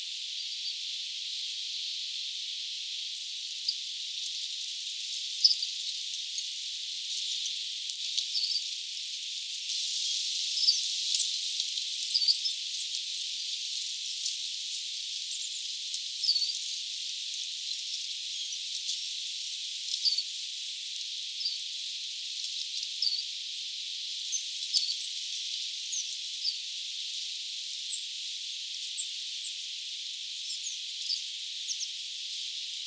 Tuuli niin kovaa, että oli vaikea saada selvää.
Toisen ruokinnan luona äänteli puu
puu ääntelee tuulessa linturuokinnalla
saaren_linturuokinnalla_puu_aantelee.mp3